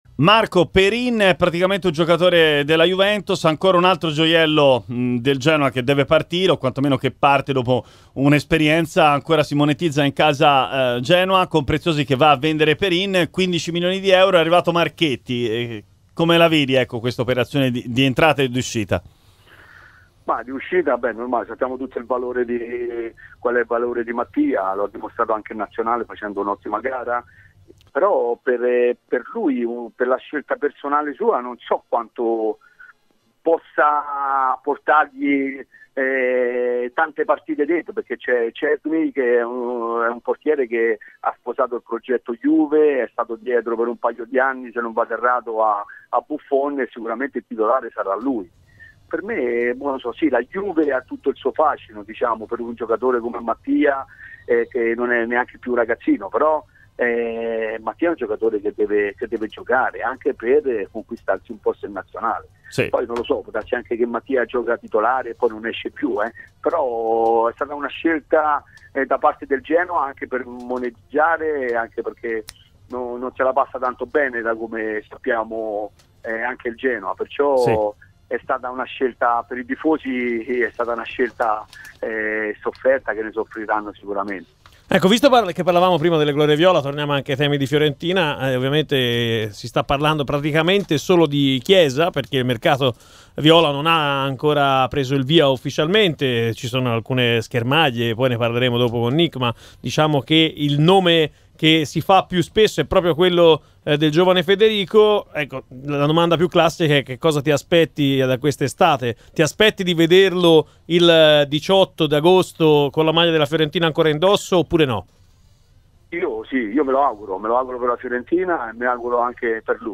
L'intervento di Marco Nappi, ex giocatore di Fiorentina, Genoa e Atalanta, in diretta nel Live Show di RMC Sport: